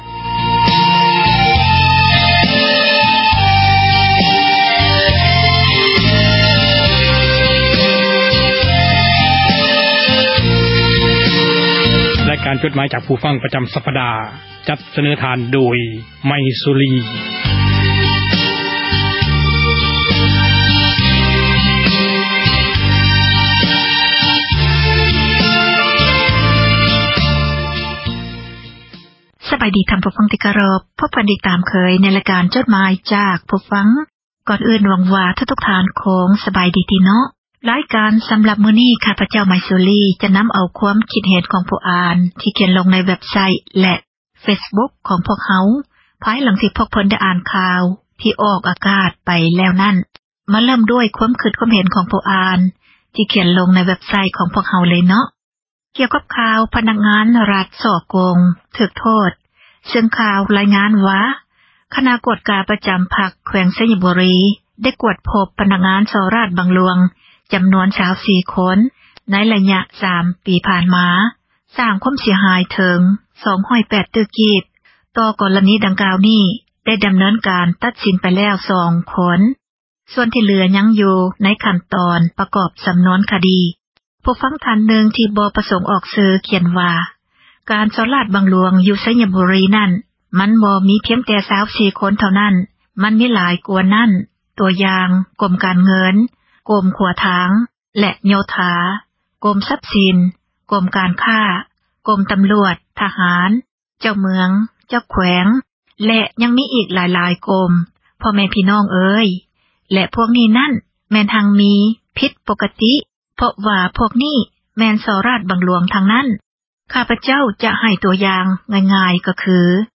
ຣາຍການ ອ່ານ ຈົດໝາຍ ຈາກ ຜູ້ຟັງ ປະຈຳ ສັປດາ ຈັດມາ ສເນີທ່ານ ໂດຍ